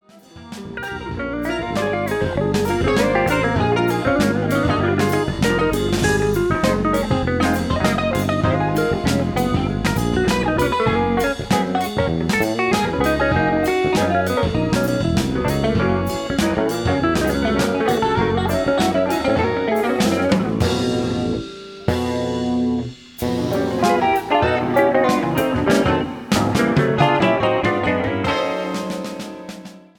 Bass, Double Bass
Drums
Recorded at Sound family, June 18 to 19, 1984.